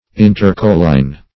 Search Result for " intercolline" : The Collaborative International Dictionary of English v.0.48: Intercolline \In`ter*col"line\, a. (Geol.)